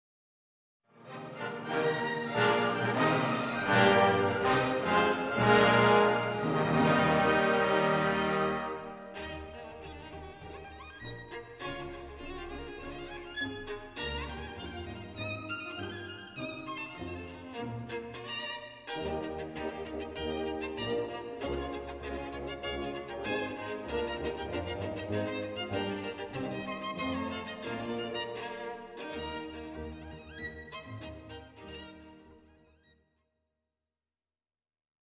February 2004 Concert
Violin Soloist - Jack Liebeck